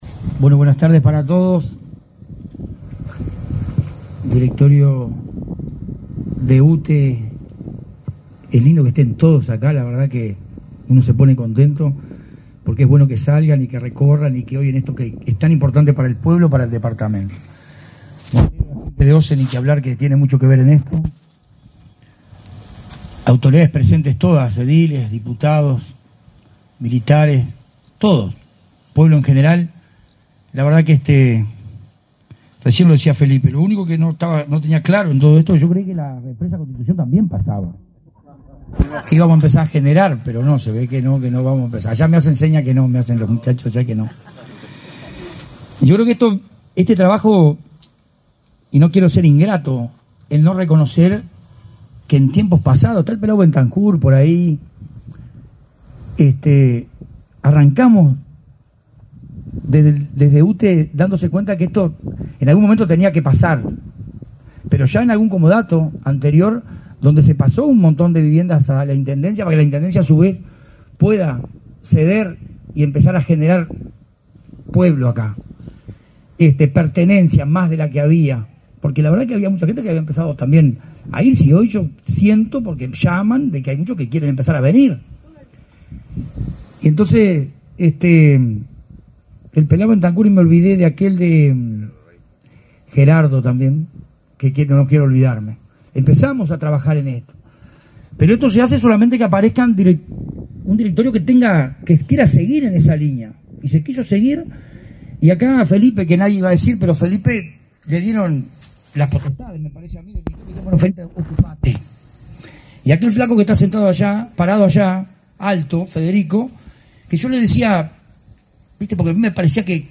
Conferencia de prensa por acuerdo para pueblo Palmar
Participaron del evento la presidenta de UTE, Silvia Emaldi; el presidente de OSE, Raúl Montero, y el intendente de Soriano, Guillermo Besozzi.